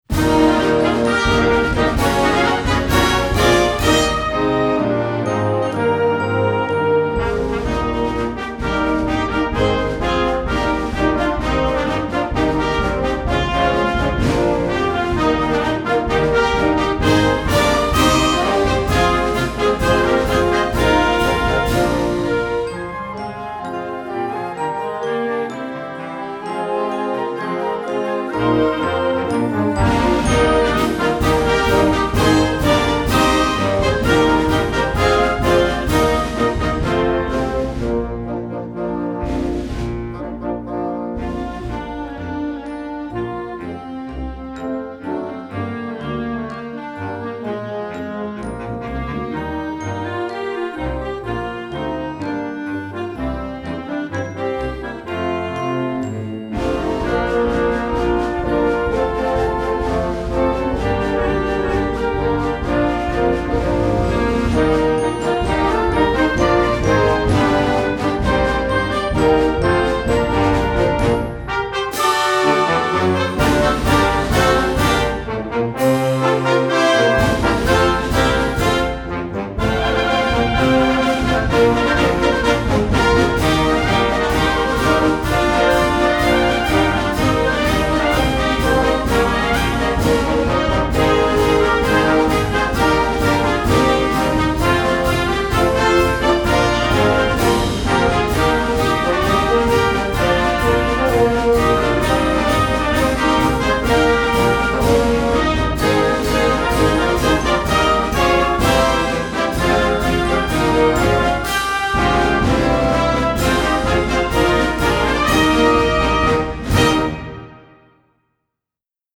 Instrumental Concert Band Marches
Concert Band